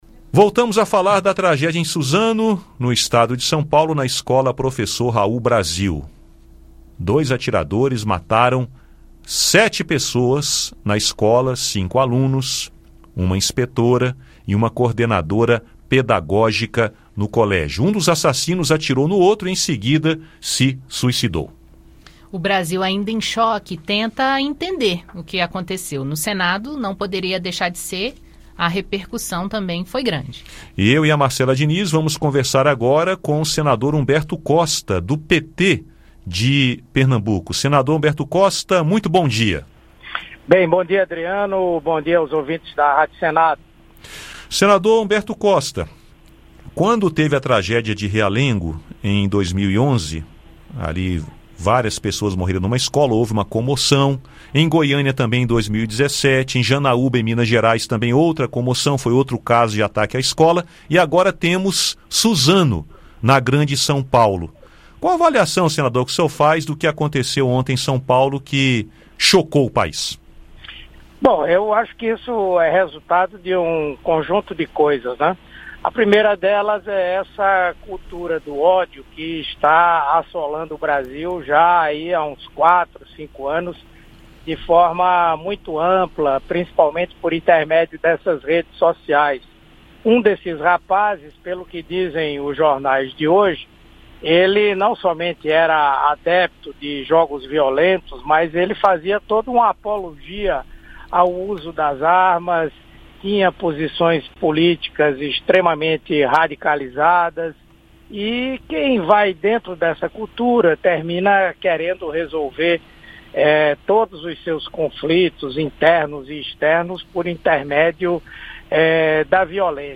Humberto Costa foi entrevistado pelos jornalistas